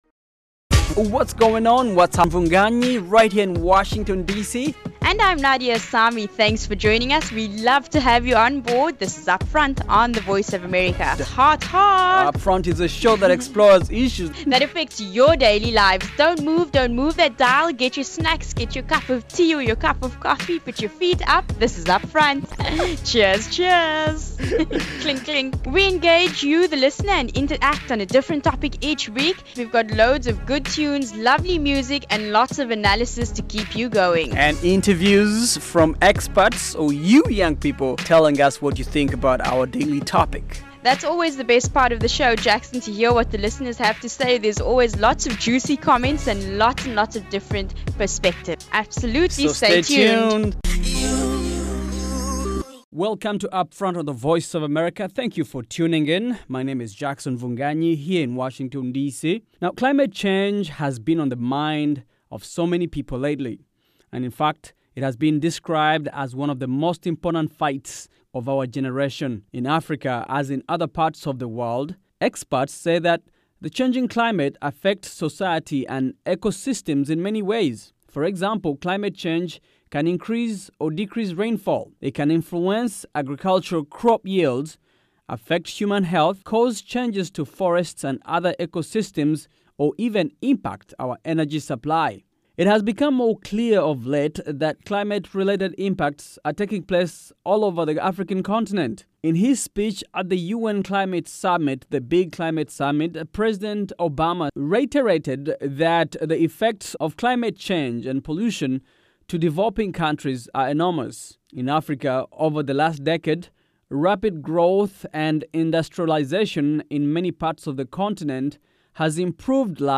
On this fresh, fast-paced show
talk to teens and young adults